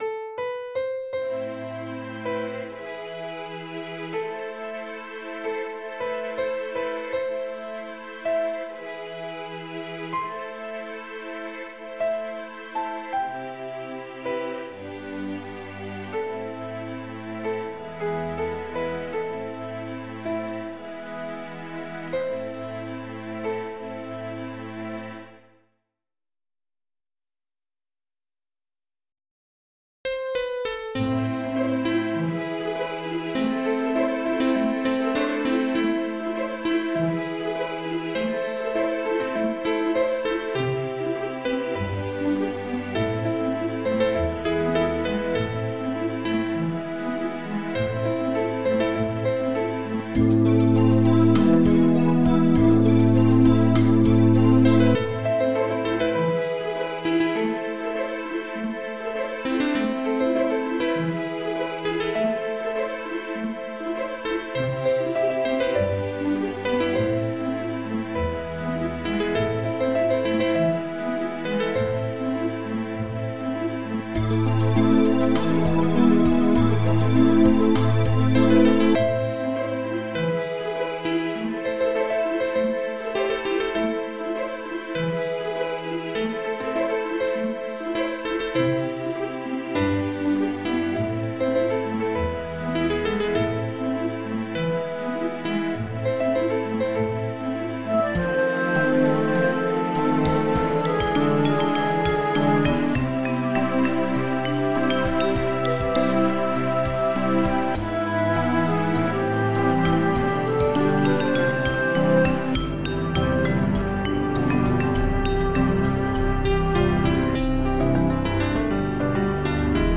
♪ Pf
♪ Fl
♪ Vib